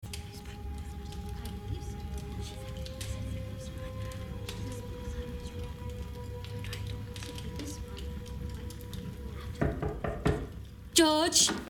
There a nifty bit of sound work going on, too. You can hear her maids talking in the background. There’s one conversation going on about fabric or something, but another, whispered that you can only hear bit of underneath.